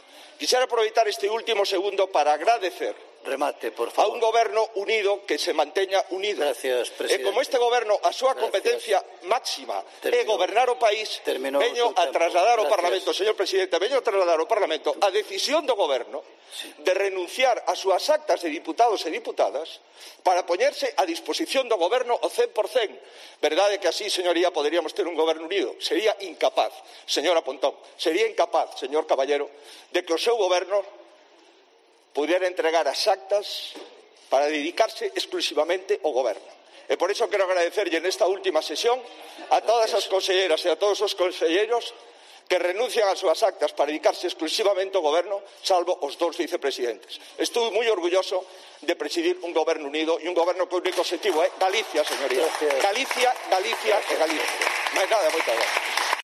Un emocionado Alberto Núñez Feijóo anuncia la salida de la mayor parte de su gobierno de la Cámara gallega